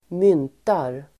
Uttal: [²m'yn:tar]